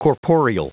Prononciation du mot corporeal en anglais (fichier audio)
Prononciation du mot : corporeal